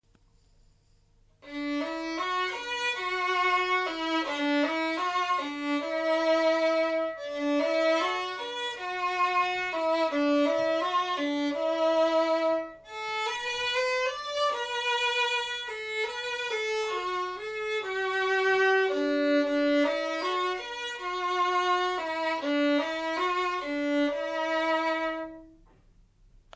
This "homemade" instrument offers- partly through the fine hand varnishing a warm and brigh tone.
Zvukové skúšky